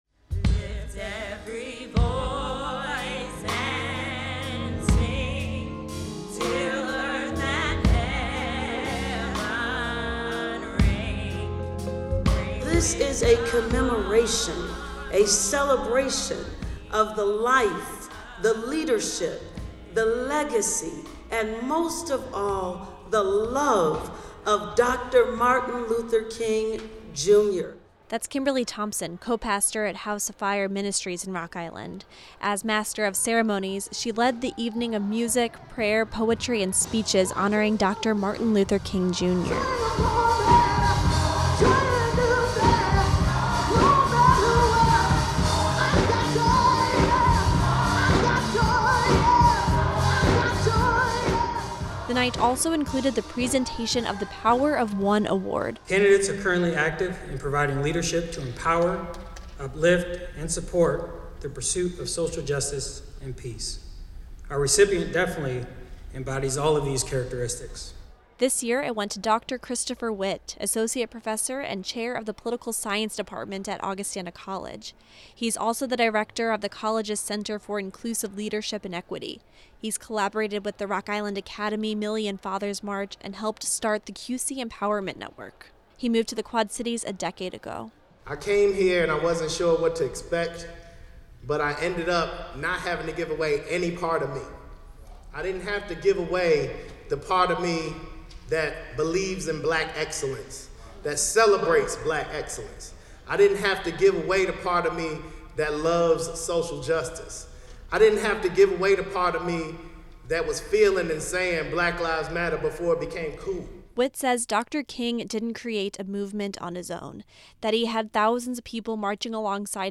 Quad Cities residents gathered at Augustana College over the weekend for a community celebration and musical tribute to honor Dr. Martin Luther King, Jr.